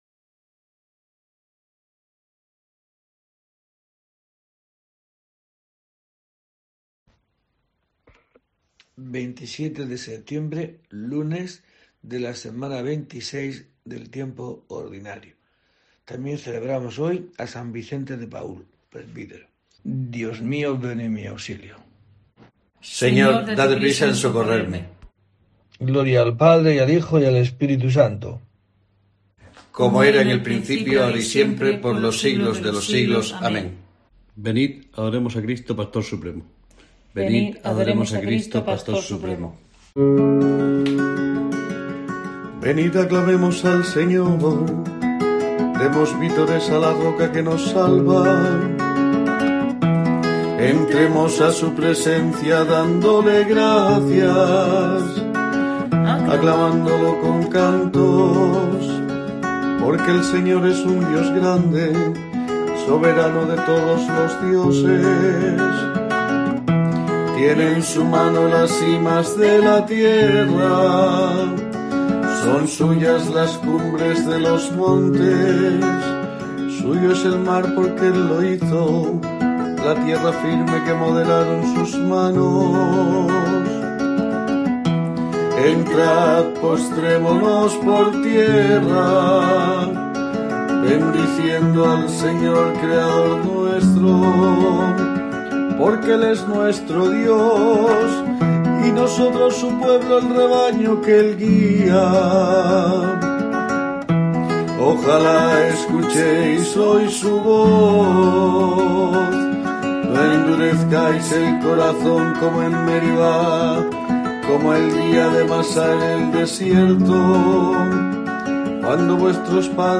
Laudes